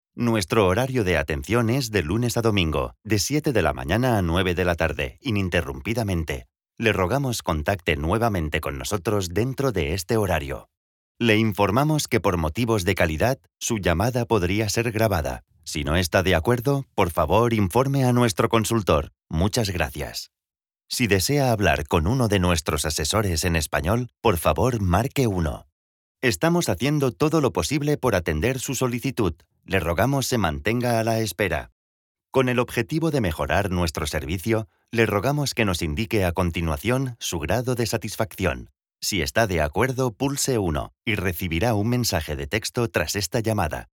IVR